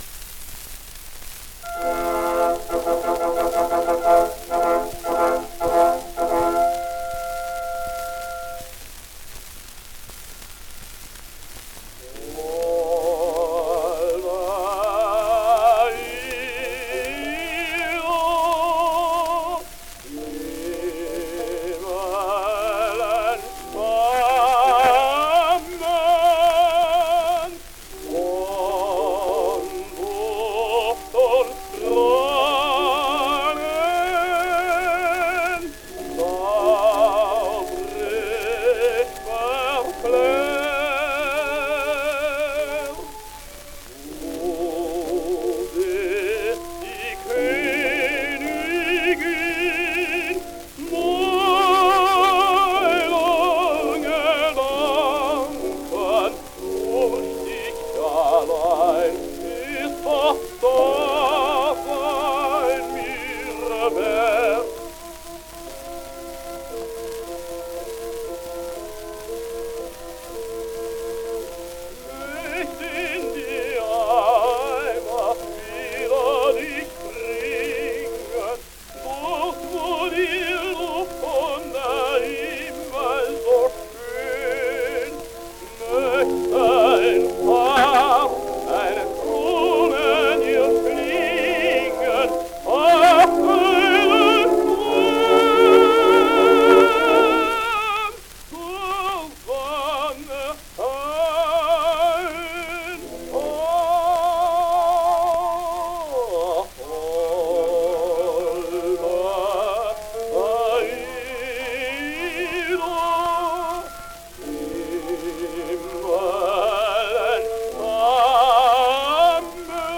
A mystery tenor.